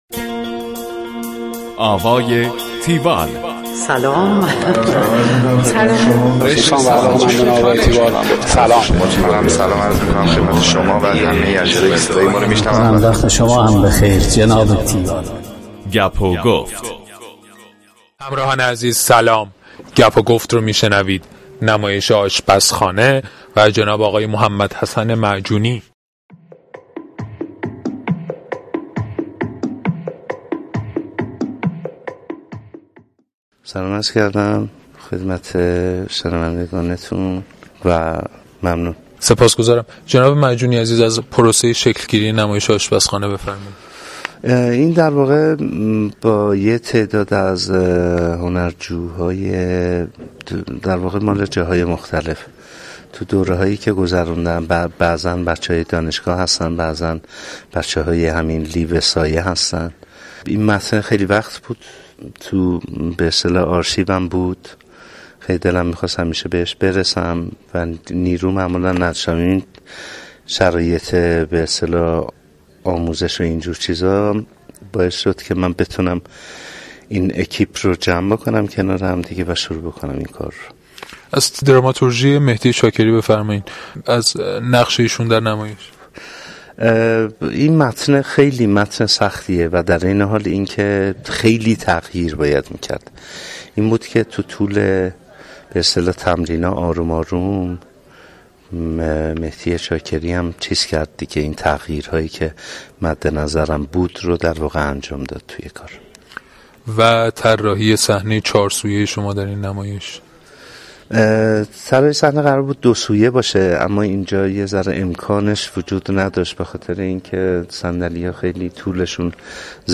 گفتگوی تیوال با محمد حسن معجونی
tiwall-interview-mohamadhasanmajooni.mp3